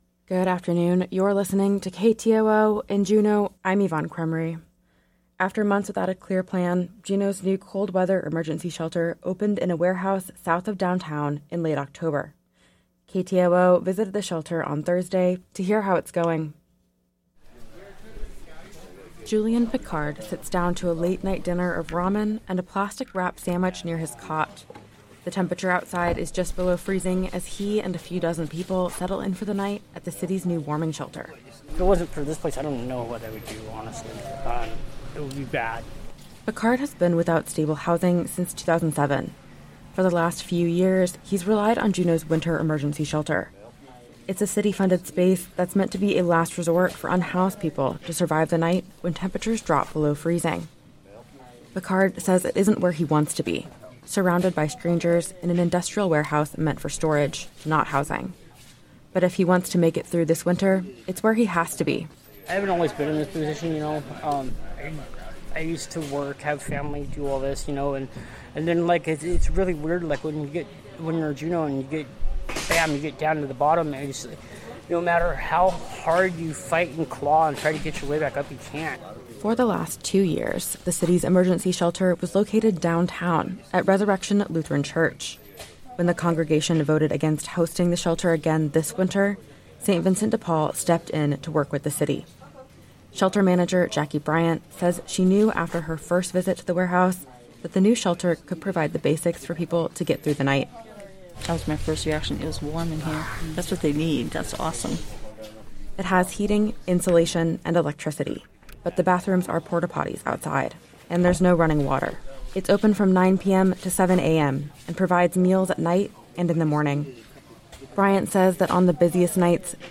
Informationcast, Tuesday December. 26th 2023